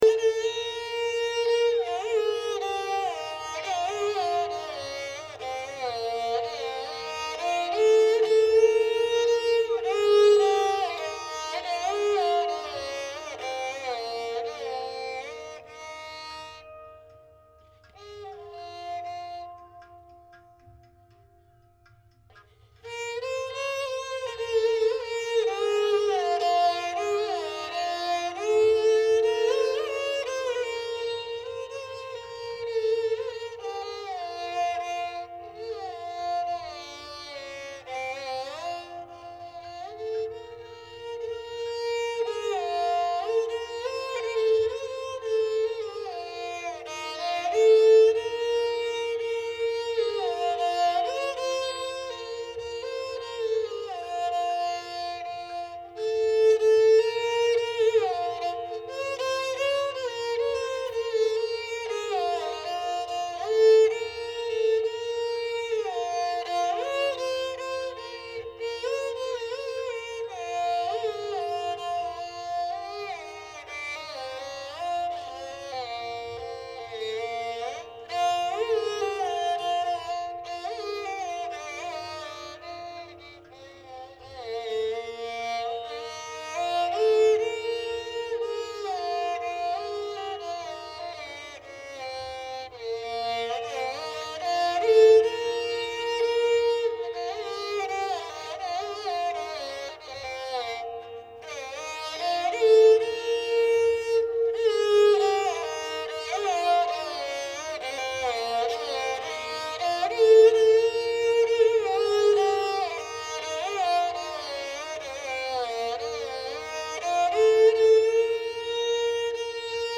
It is of Khamaj Thaat.
Few illustrations of Raag Maajh:
Dilruba 3:
dilruba_maajh3.mp3